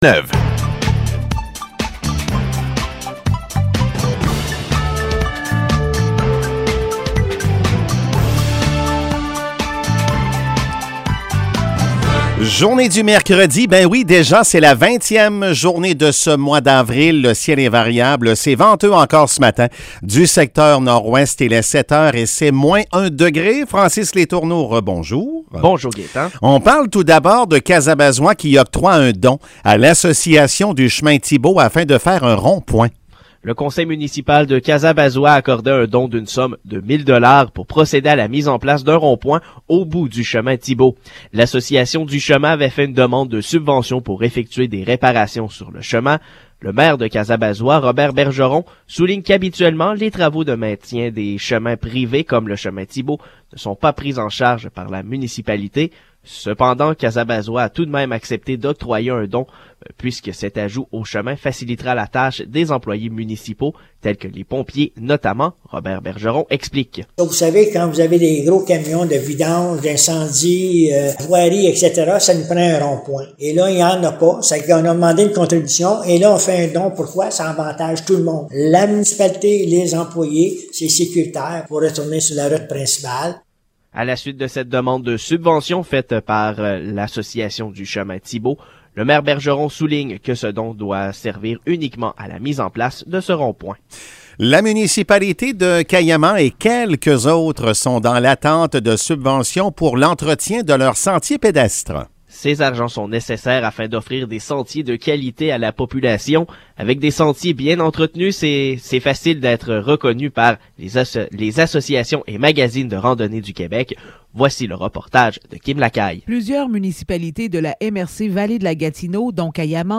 Nouvelles locales - 20 avril 2022 - 7 h